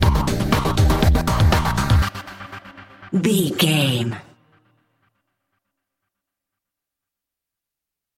Epic / Action
Aeolian/Minor
drum machine
synthesiser
electric piano
90s